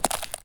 Add sound for clicking the card
frozen_click.ogg